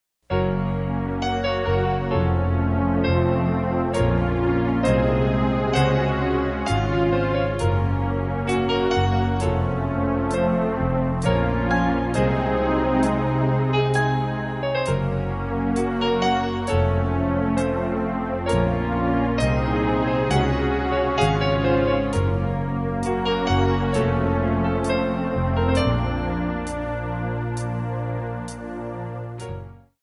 Backing track files: All (9793)